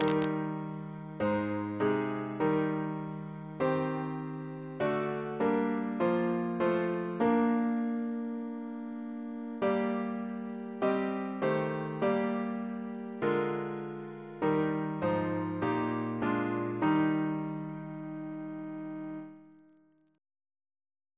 Double chant in E♭ Composer: John Naylor (1838-1897), Organist of York Minster Reference psalters: H1982: S436